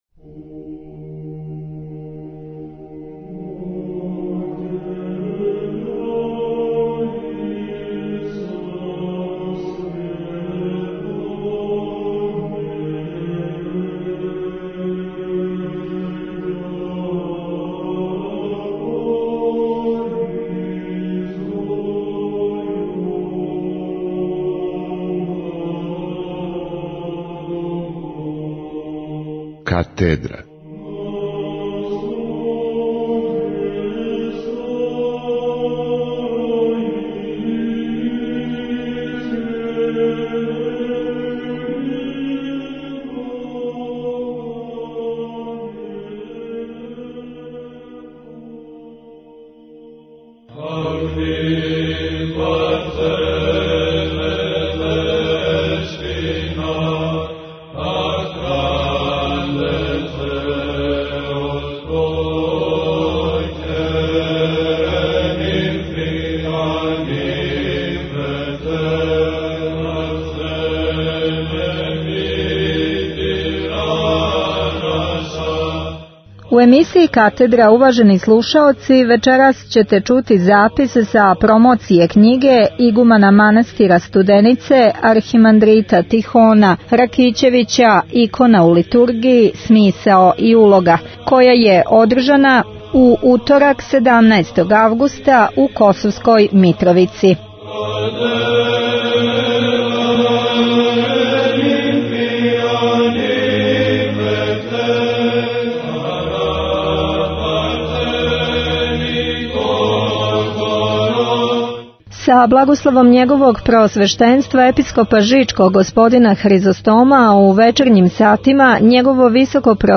Митрополит Амфилохије је изговорио поздравно слово